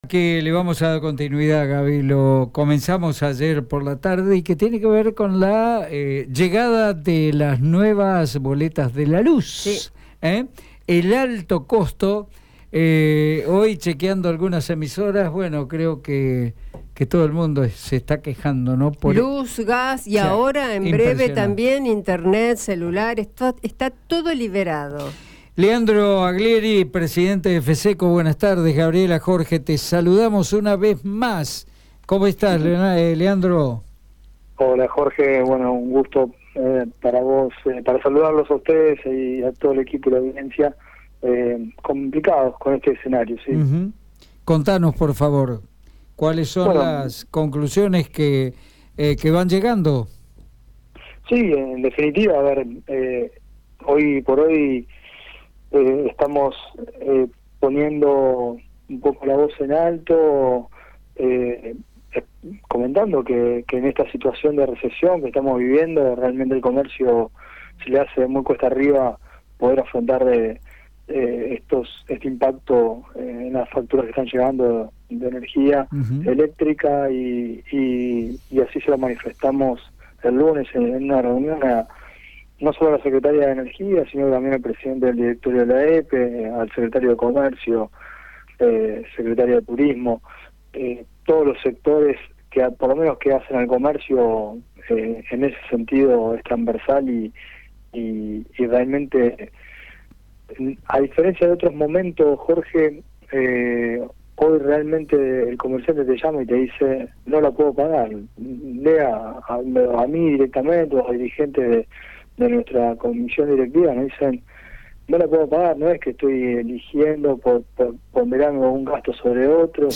La intención de la reunión fue analizar los aumentos en las tarifas de la luz. En diálogo con RADIO EME